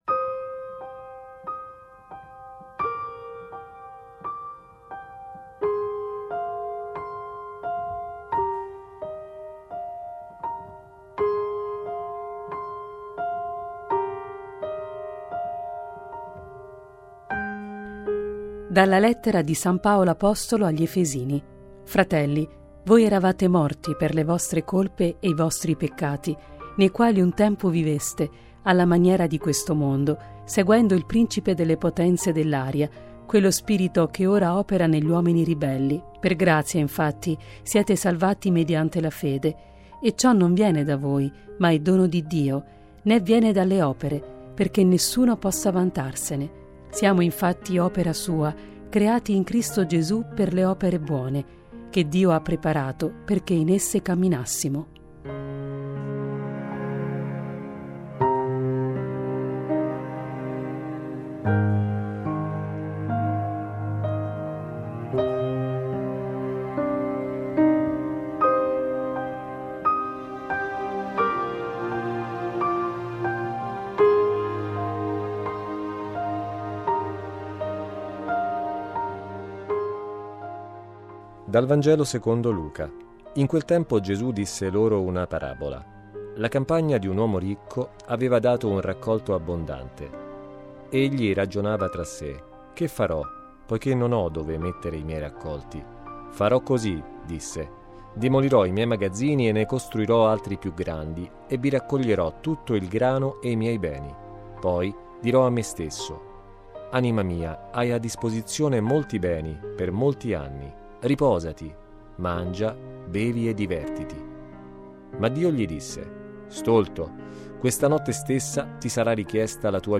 Le letture del giorno (prima e Vangelo) e le parole di Papa Francesco da VaticanNews: